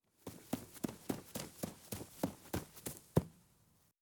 小孩从远到近跑步.wav